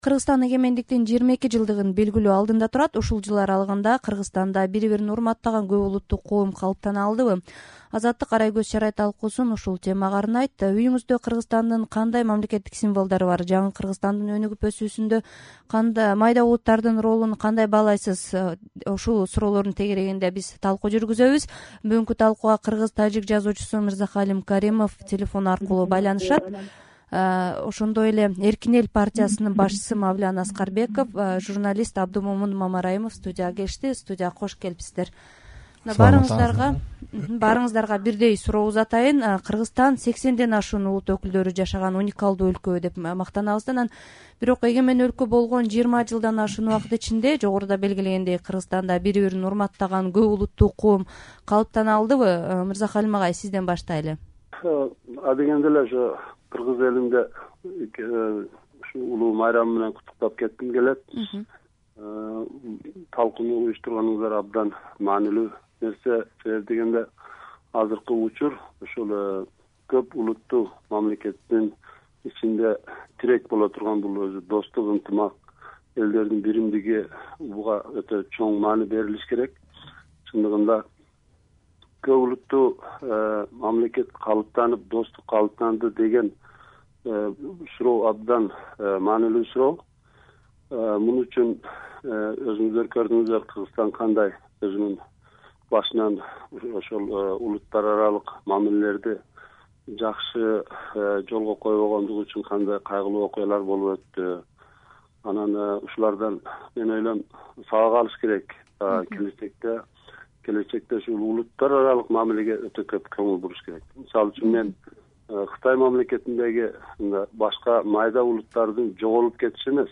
Эгемендик тууралуу талкуу (1-бөлүк)